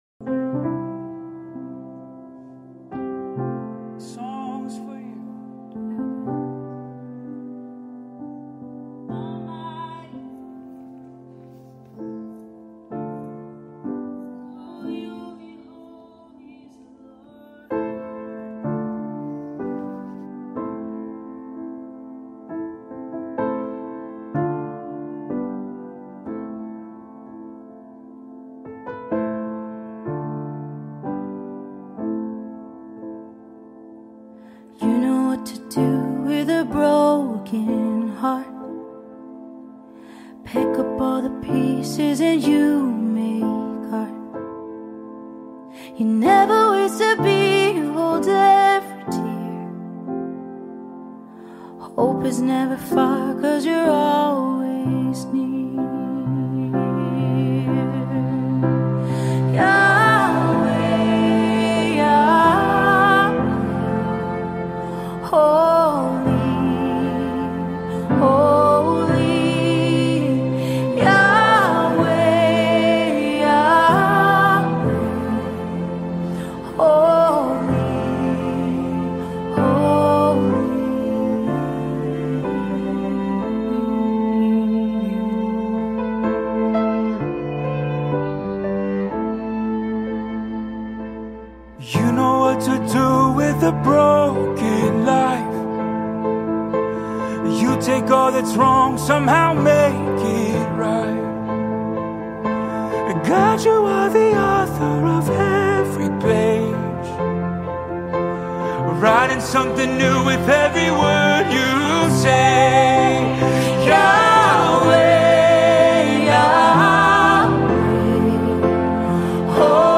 Jesus Worship